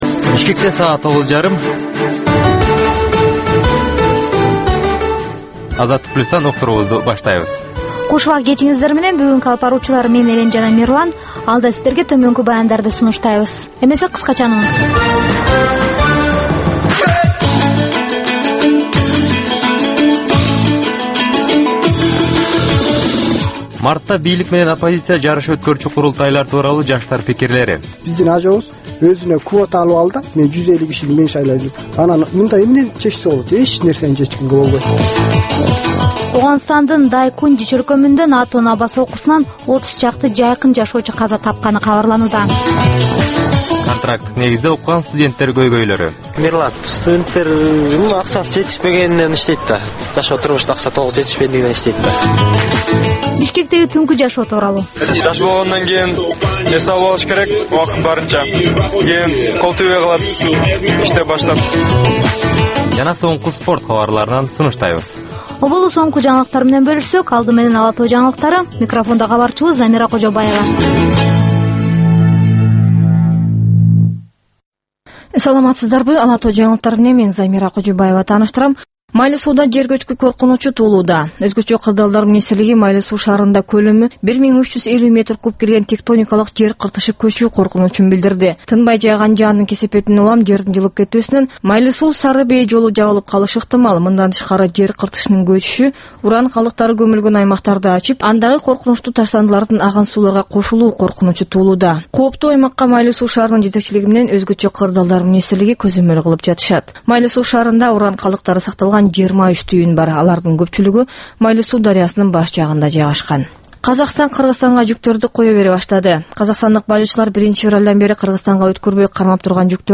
Бул жаштарга арналган кечки үналгы берүү жергиликтүү жана эл аралык кабарлардан, репортаж, маек, баян жана башка берүүлөрдөн турат. "Азаттык үналгысынын" бул жаштар берүүсү Бишкек убактысы боюнча саат 21:30дан 22:00ге чейин обого чыгат.